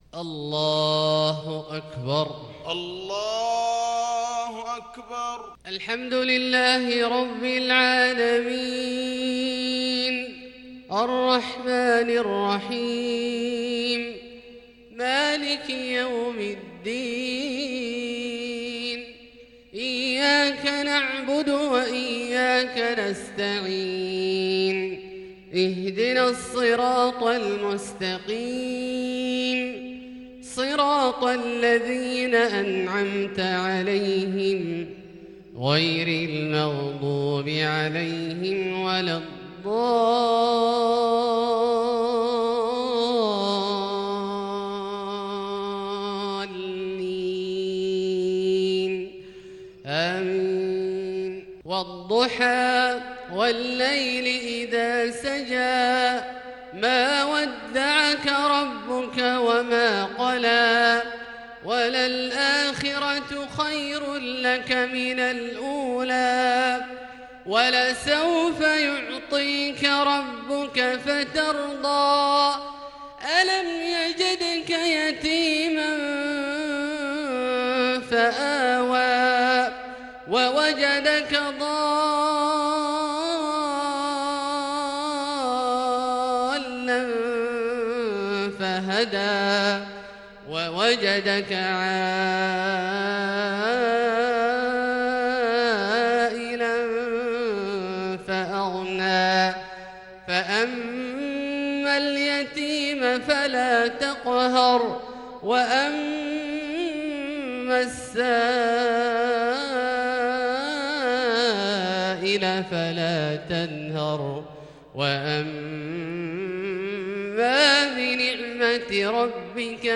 صلاة المغرب للقارئ عبدالله الجهني 2 ربيع الأول 1442 هـ
تِلَاوَات الْحَرَمَيْن .